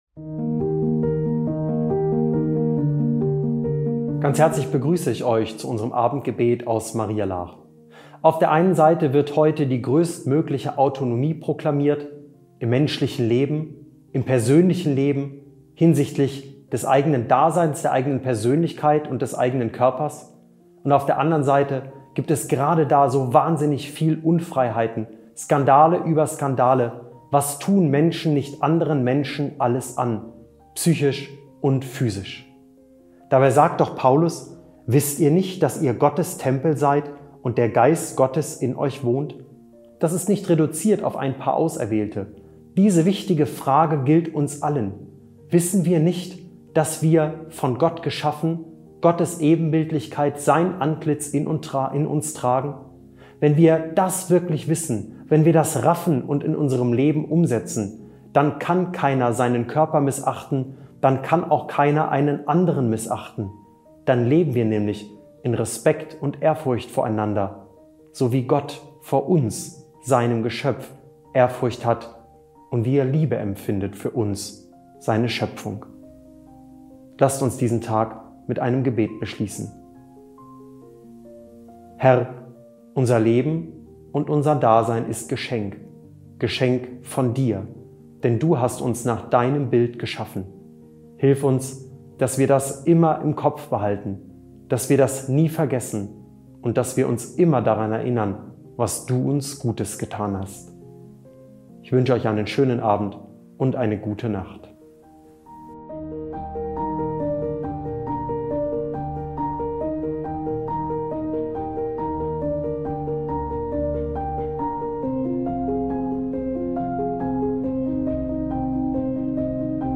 Abendgebet – 2. August 2025